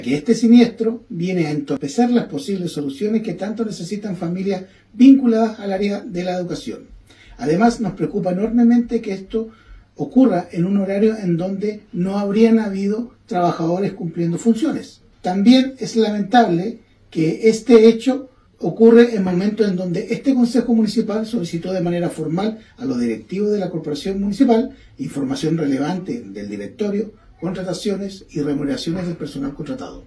Durante la lectura de la declaración pública el concejal Andrés Ibáñez, señaló que es lamentable que este siniestro ocurre en un momento en donde el Concejo Municipal solicitó de manera formal, a los directivos de la Corporación Municipal, información relevante del directorio.
Durante la lectura de la declaración, el concejal Ibáñez, indicó que en el mes de enero acudieron a Contraloría para solicitar que se investiguen las cuentas de Salud y Educación.